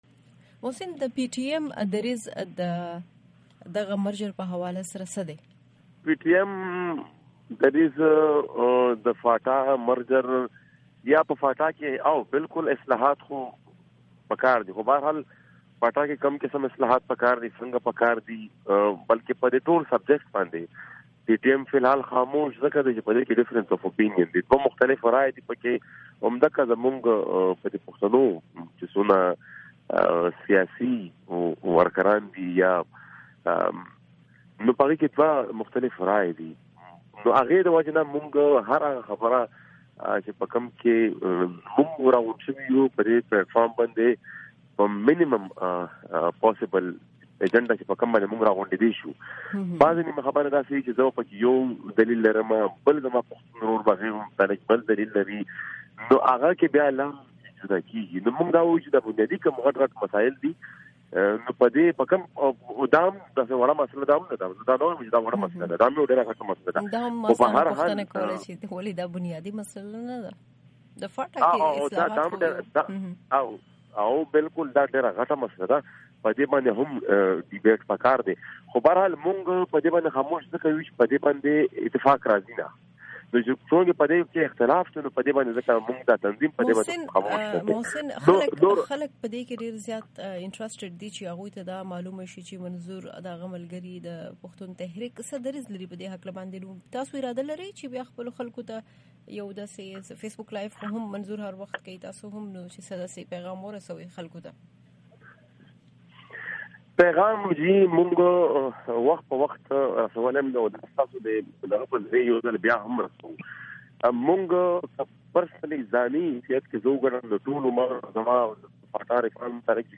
د محسن داوړ مرکه